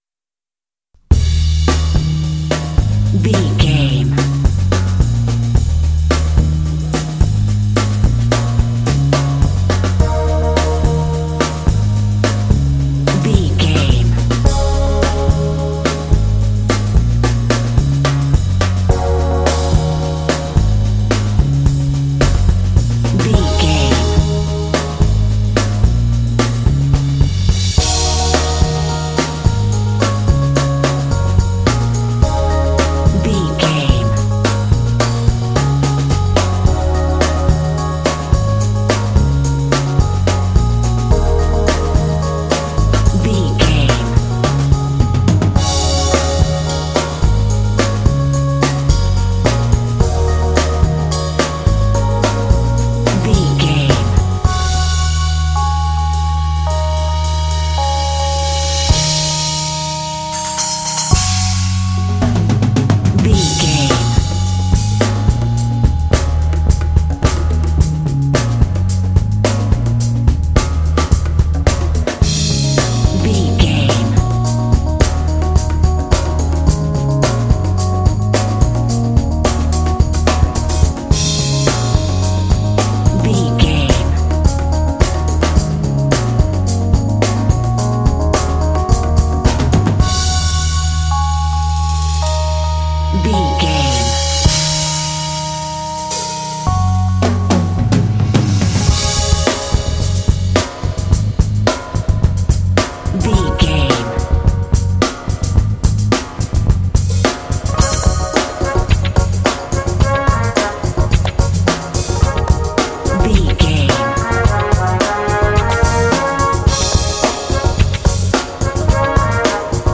Funky, chill track, ideal for cool action games.
Aeolian/Minor
E♭
smooth
bass guitar
drums
electric piano
brass
Funk
Lounge